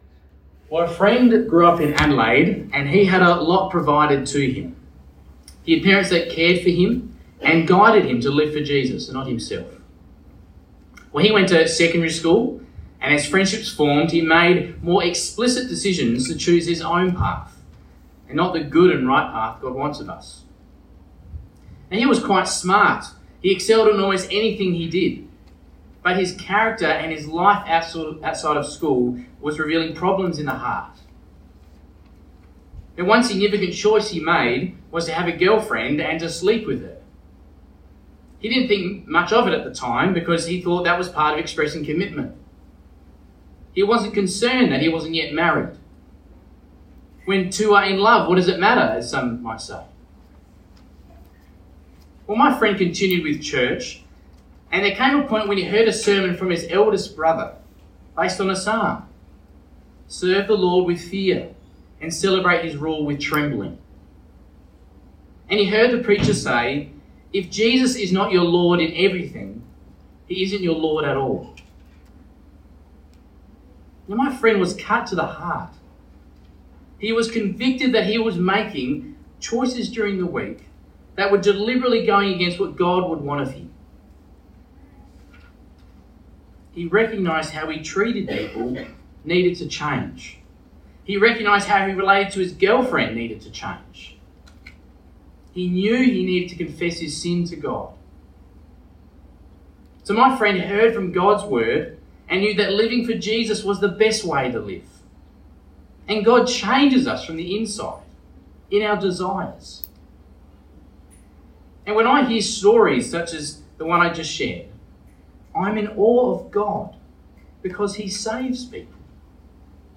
A message from the series "God's Big Picture."